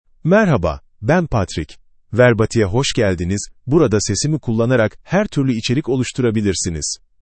MaleTurkish (Turkey)
PatrickMale Turkish AI voice
Patrick is a male AI voice for Turkish (Turkey).
Voice sample
Patrick delivers clear pronunciation with authentic Turkey Turkish intonation, making your content sound professionally produced.